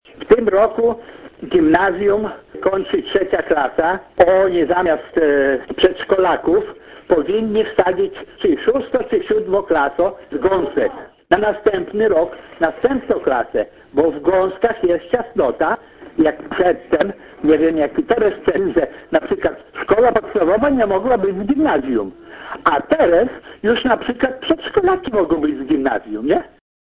Na takie rozwiązanie nie zgadzają się mieszkańcy miejscowości Świdry i proponują, by do Kijewa przenieść część oddziałów ze Szkoły Podstawowej w Gąskach- mówi Radiu 5 sołtys wsi Świdry Ryszard Makarewicz.